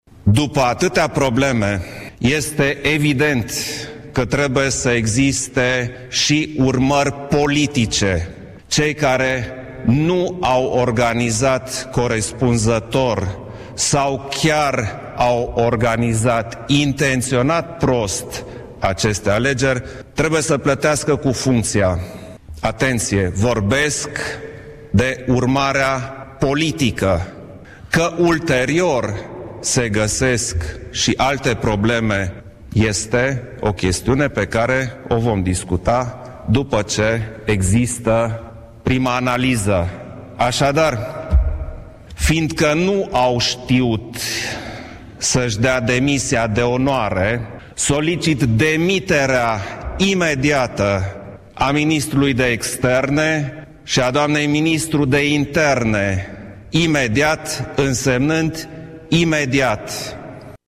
Într-o declarație de presă susținută, în această după-amiază, la Palatul Cotroceni, președintele le-a reproșat celor doi miniștri că nu au organizat corespunzător sau chiar au organizat intenționat prost aceste alegeri, motiv pentru care trebuie să plătească cu funcția: